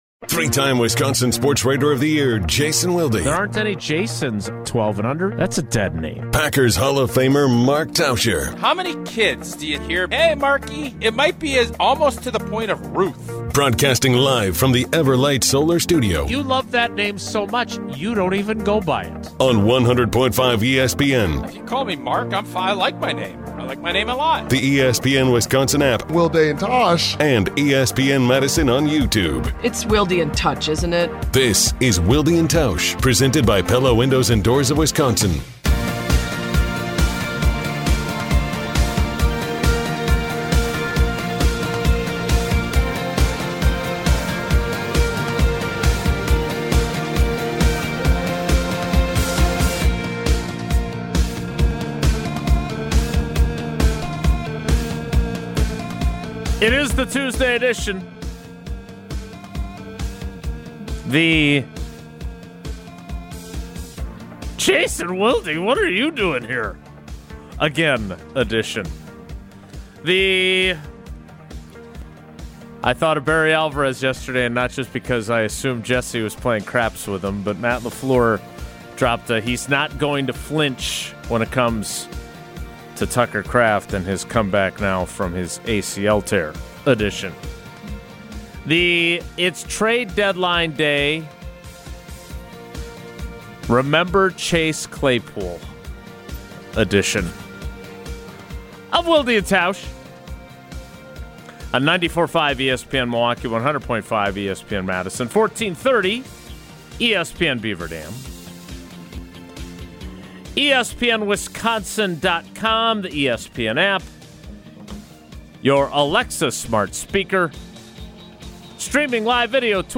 in the Everlight Solar Studio at ESPN Madison